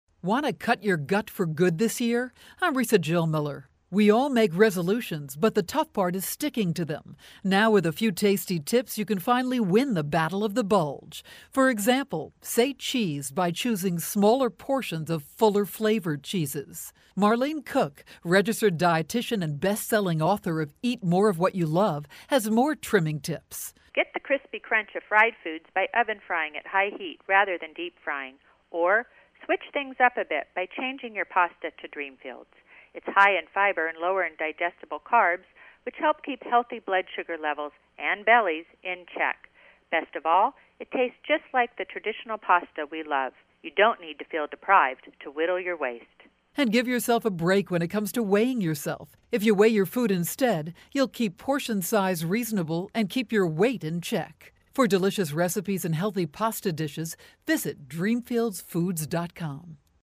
January 30, 2013Posted in: Audio News Release